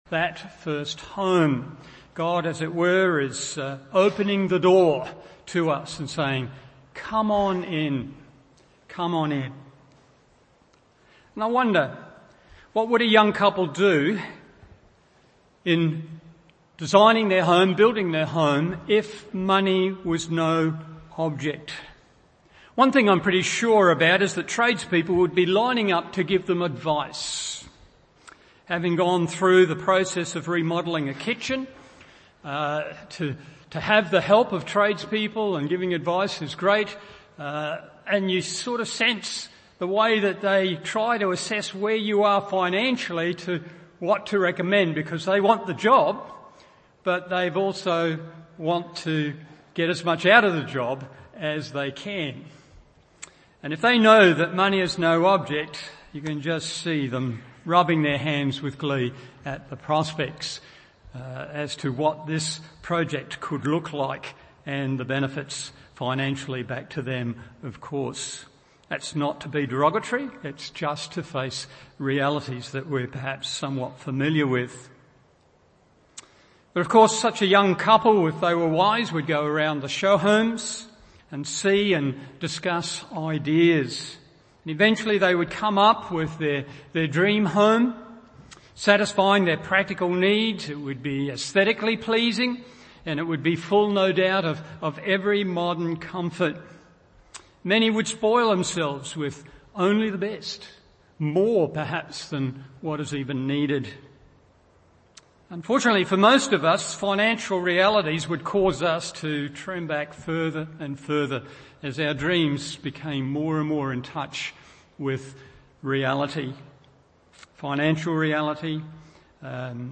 Morning Service Genesis 2:4-15 1.